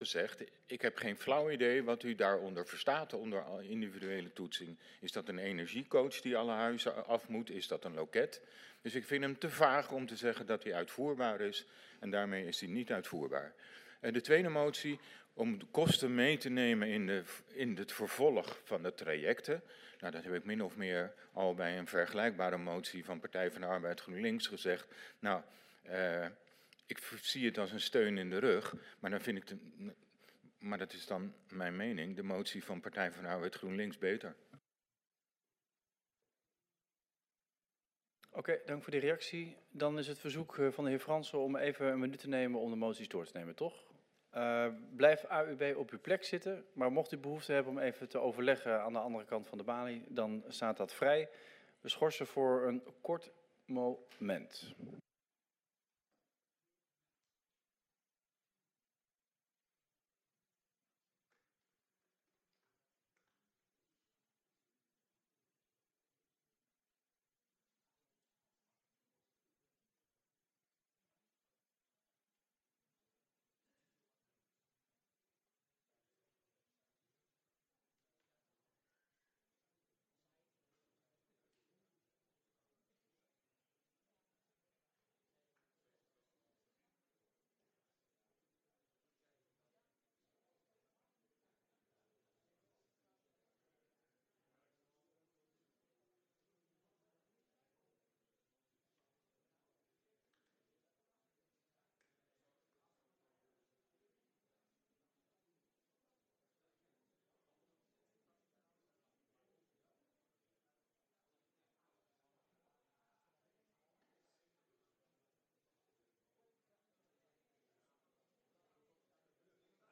Agenda aalsmeer - 3.Raad (besluitvormend) donderdag 22 mei 2025 20:00 - 23:00 - iBabs Publieksportaal
Aangezien door een technische storing de livestream rond de agendapunten inzake de benoemingen niet volledig door de livestream is uitgezonden, is hierbij het audiobestand van het laatste deel van de raadsvergadering toegevoegd.